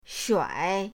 shuai3.mp3